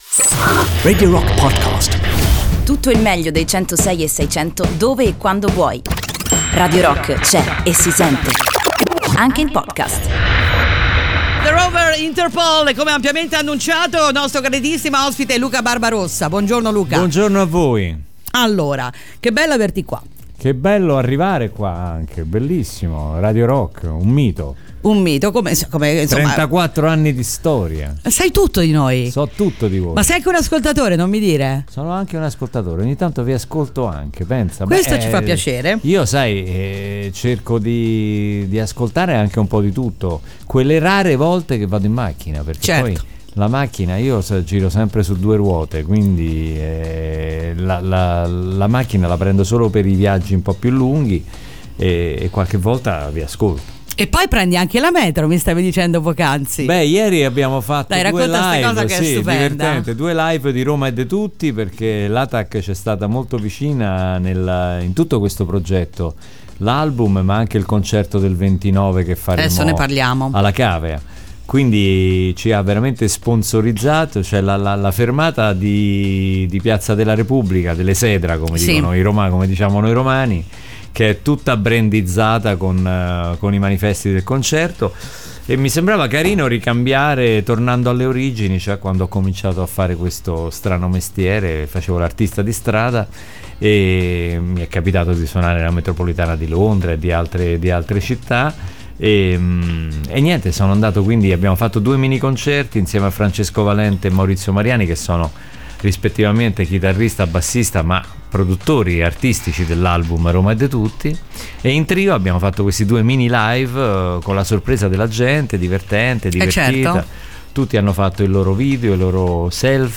Intervista: Luca Barbarossa (19-06-18)